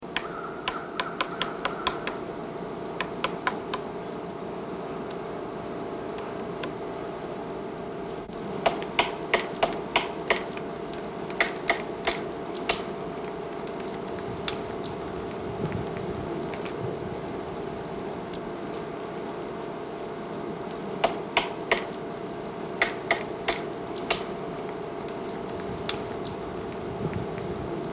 Animal Sound Effects Library
These sound clips are in the public domain (having been recorded by the U.S. Fish and Wildlife Service)
woodpecker.mp3